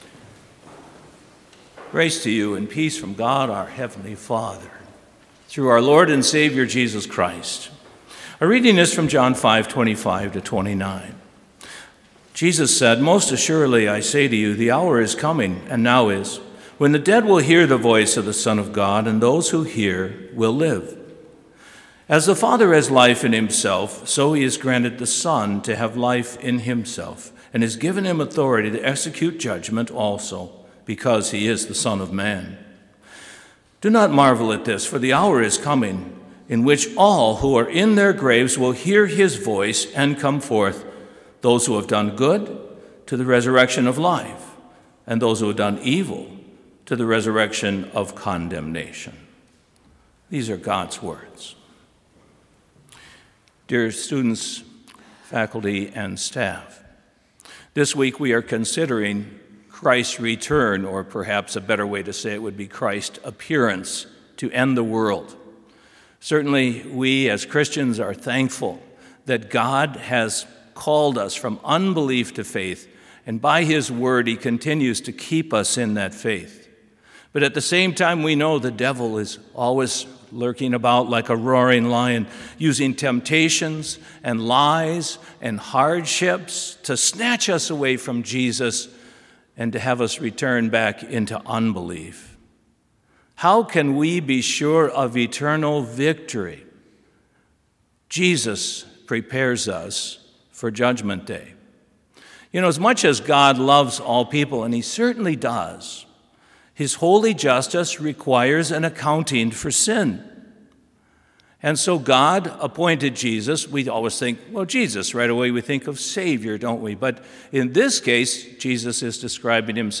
Complete service audio for Chapel - November 15, 2021